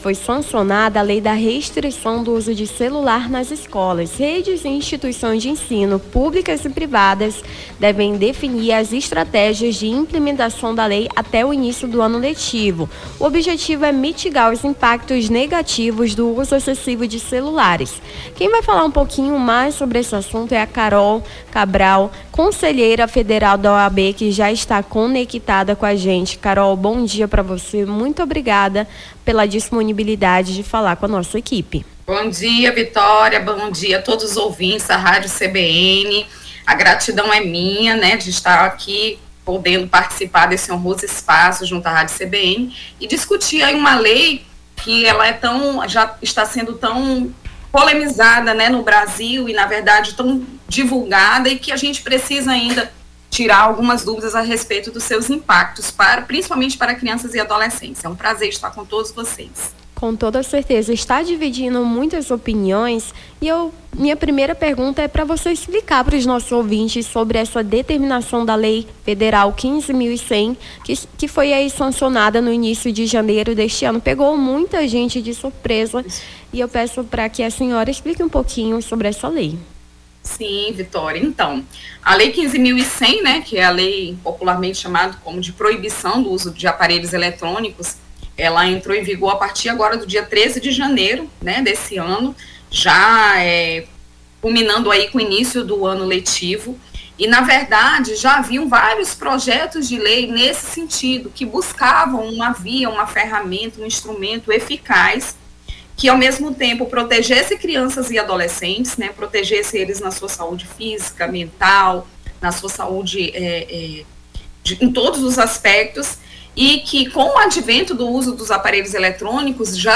Nome do Artista - CENSURA - ENTREVISTA LEI PROIBIÇÃO CELULARES EM ESCOLAS (10-02-25).mp3